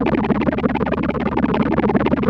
3100 FFA C#3.wav